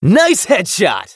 hotshot_kill_06.wav